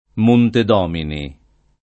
m1nte d0mini] top. (Tosc.) — come nome di ospizio in Firenze (la «pia casa di lavoro»), anche Montedomini [id.] — come nome di castello diroccato nel Valdarno di Sopra, anche Monte Domenichi [m1nte dom%niki]